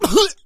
PigHit 13.wav